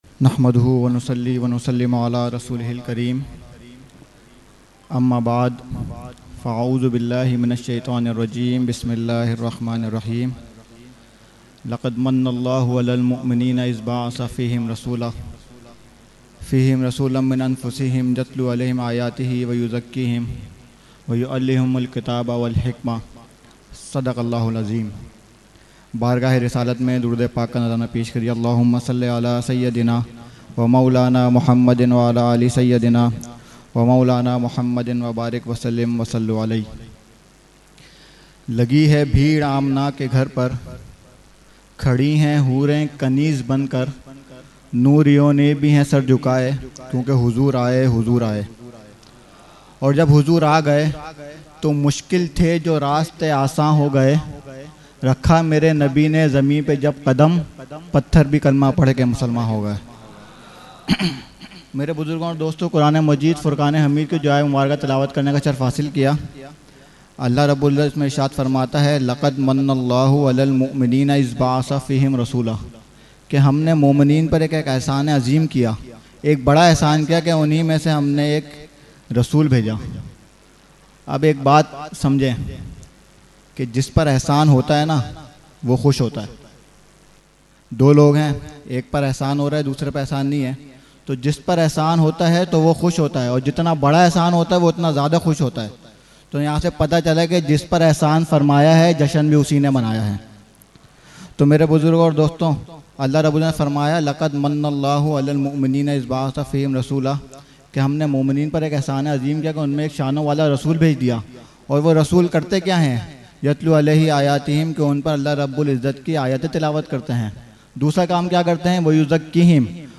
Mehfil e Jashne Subhe Baharan held on 16 September 2024 at Dargah Alia Ashrafia Ashrafabad Firdous Colony Gulbahar Karachi.
Category : Speech | Language : UrduEvent : Jashne Subah Baharan 2024